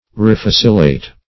Search Result for " refocillate" : The Collaborative International Dictionary of English v.0.48: Refocillate \Re*foc"il*late\ (r?*f?s"?l*l?t), v. t. [L. refocillatus, p. p. of refocillare; pref. re- re- + focillare to revive by warmth.] To refresh; to revive.